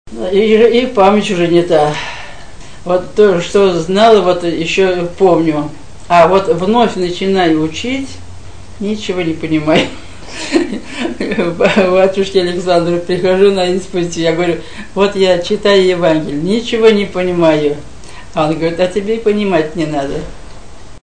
The pedal turns the old Singer machine, and stitches bind a seam in the fabric.